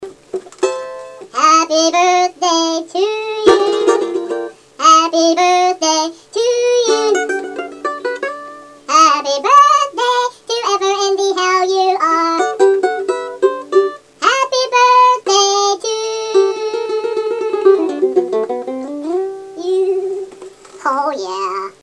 Guitar lessons finally pay off.